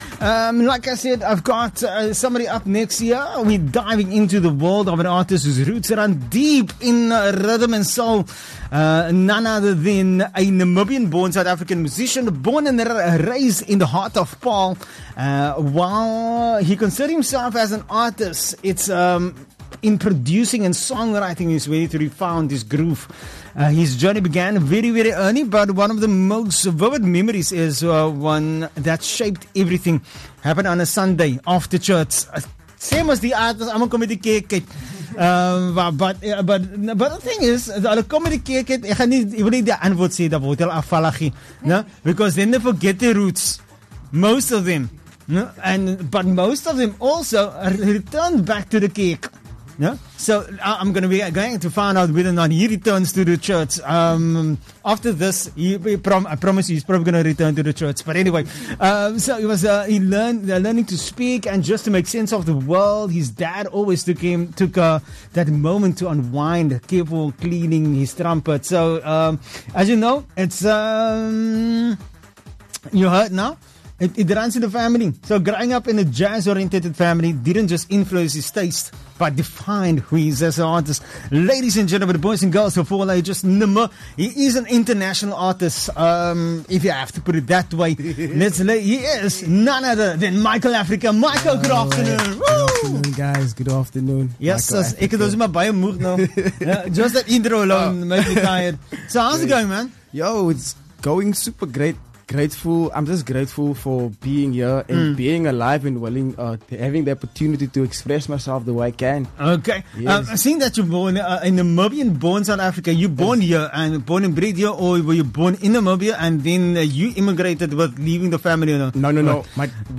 12 Apr Artist Interview